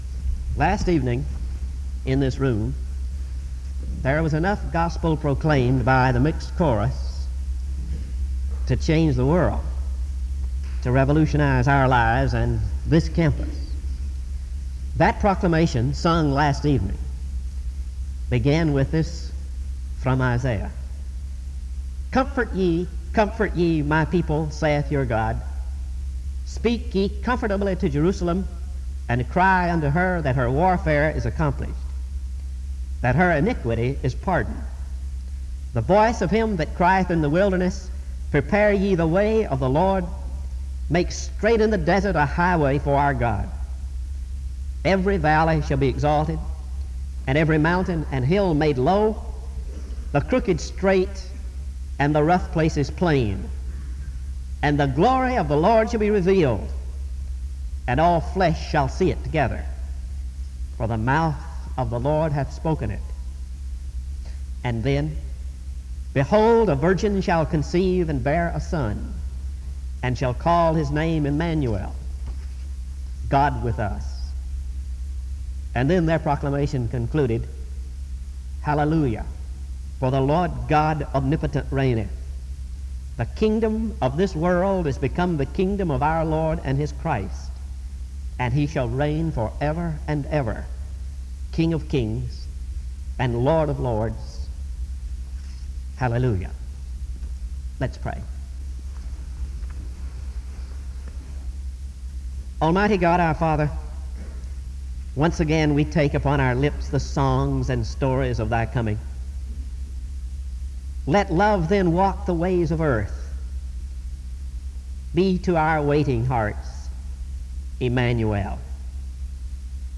He offers a prayer from 1:39-3:11.
In Collection: SEBTS Chapel and Special Event Recordings - 1970s